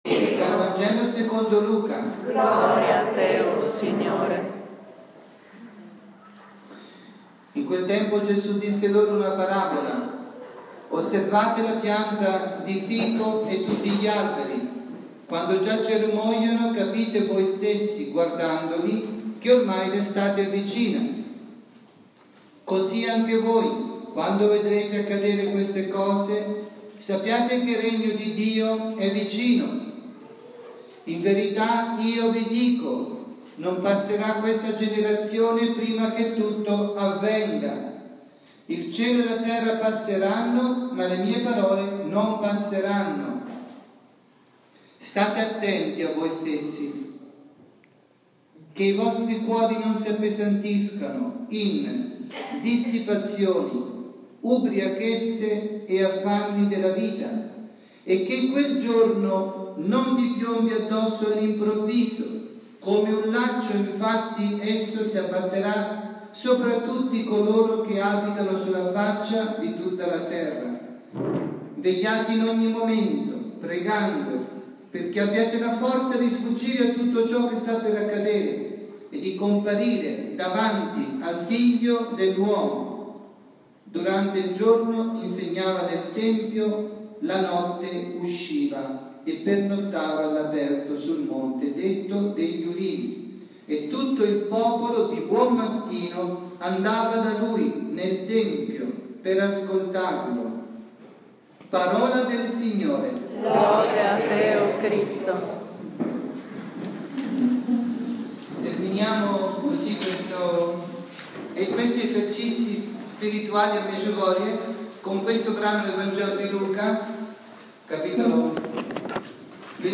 Esercizi spirituali “Cercate le cose di lassù” Medugorje, 22-28 ottobre 2015